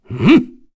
Kain-Vox_Attack2_kr.wav